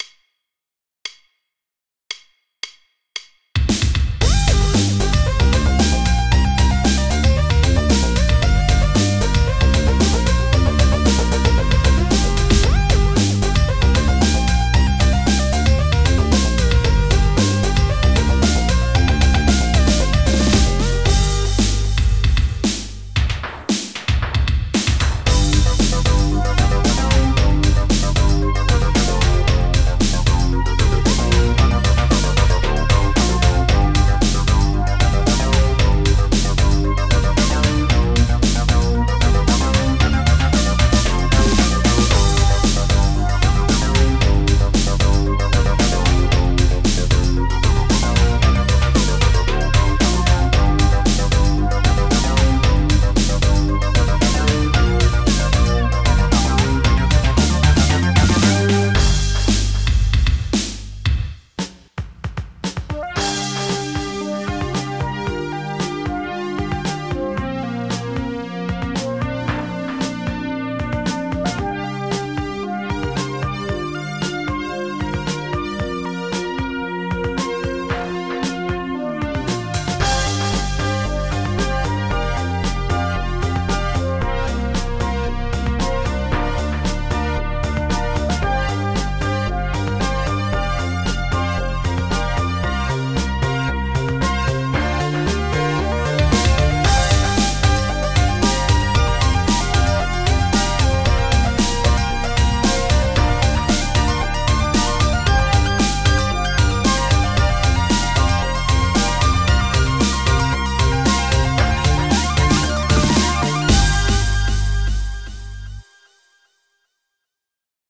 this track is a lot more varied and more upbeat